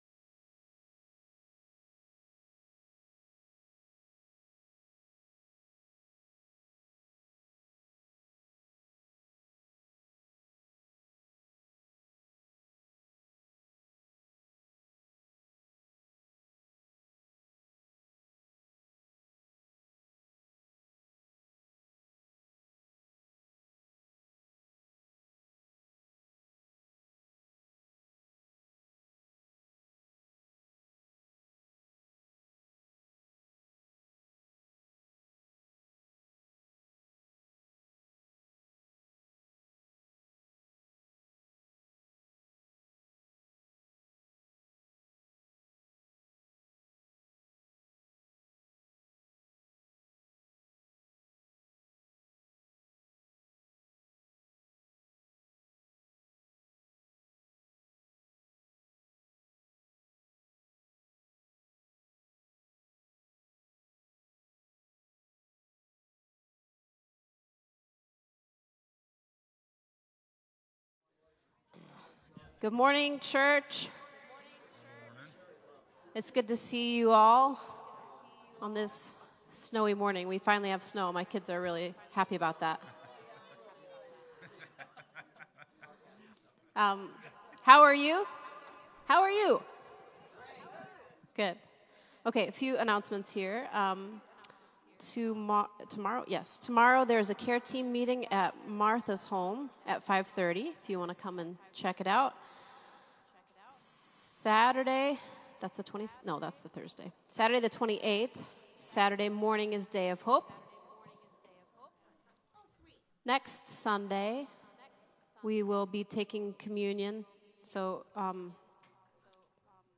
Praise Worship